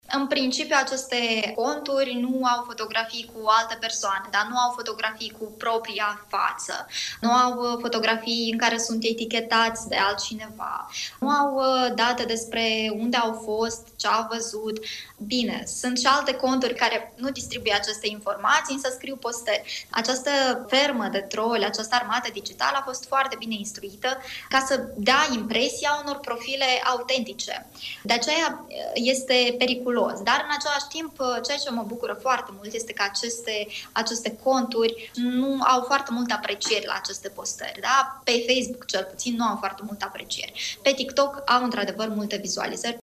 în emisiunea „Imperativ”, la Radio Iași